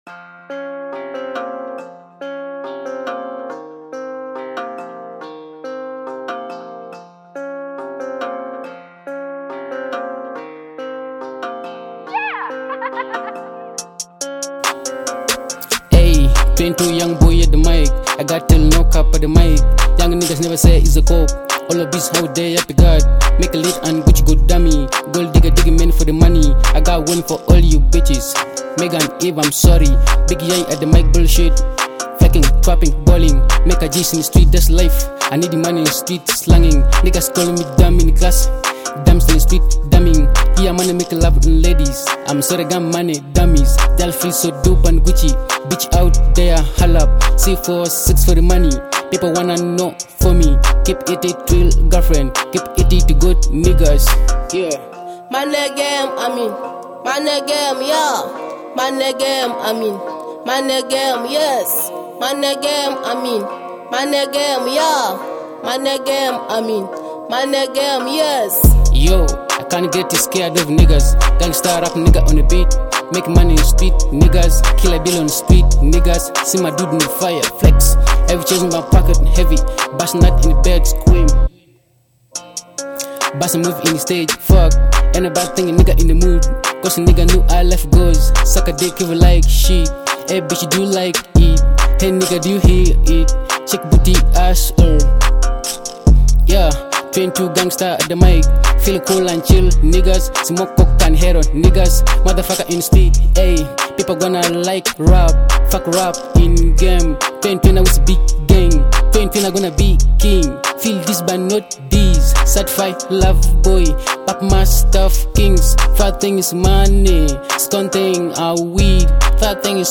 a hard-hitting hip-hop banger
packed with bold bars, heavy beats, and streetwise energy.